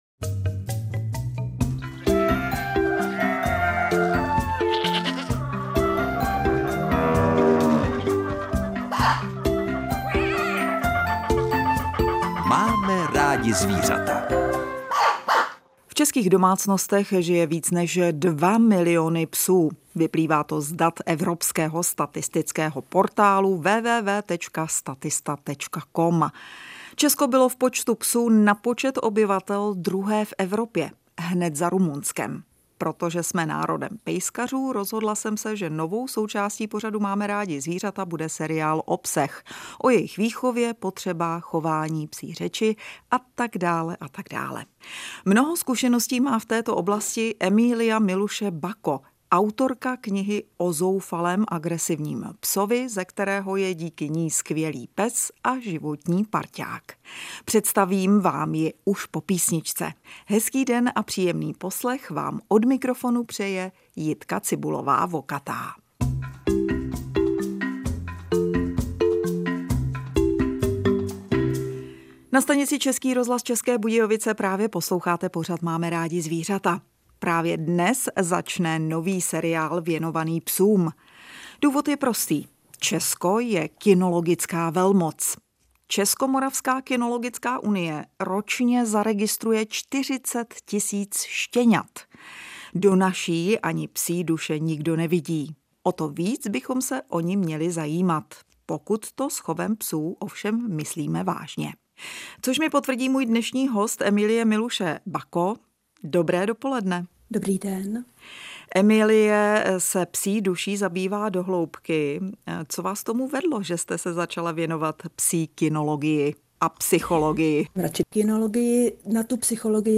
Reportáže z jižních Čech, písničky na přání a dechovka.